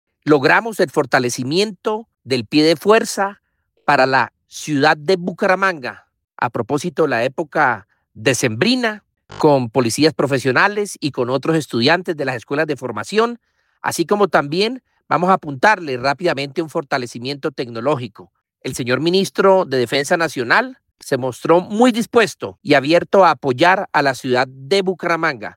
Alcalde encargado Bucaramanga, Javier Sarmiento